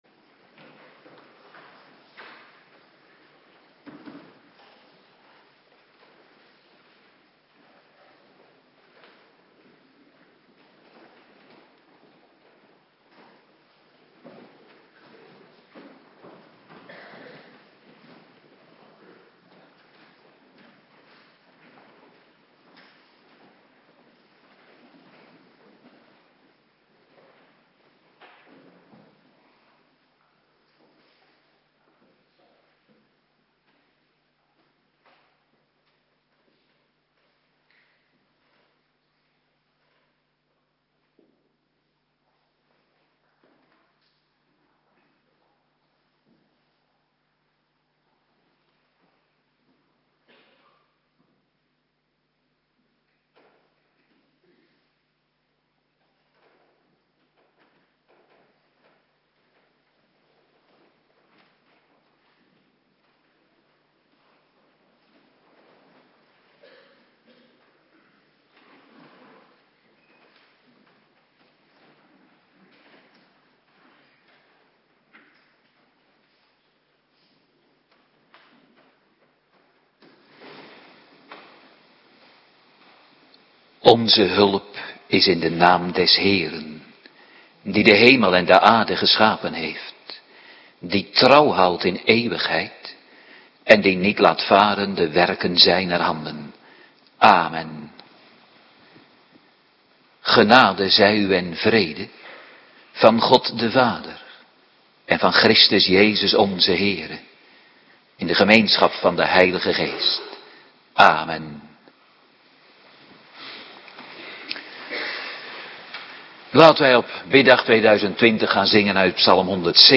Avonddienst biddag
Locatie: Hervormde Gemeente Waarder